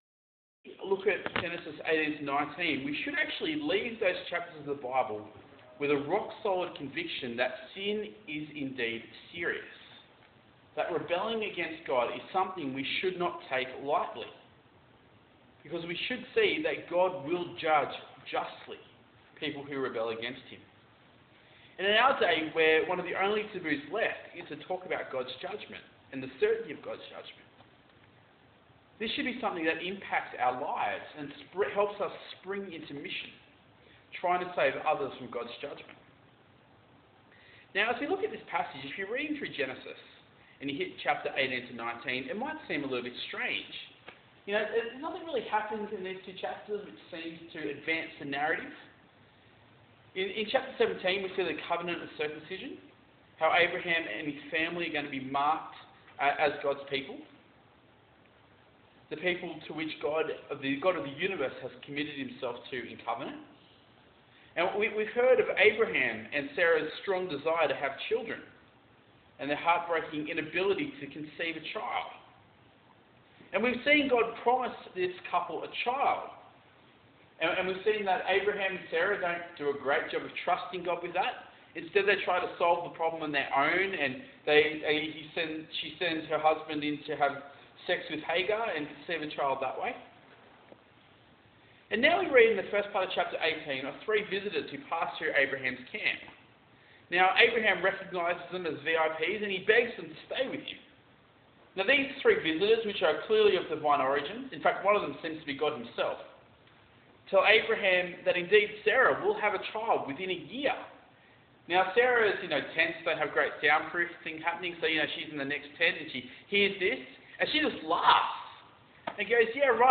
Passage: Genesis 18-19 Talk Type: Bible Talk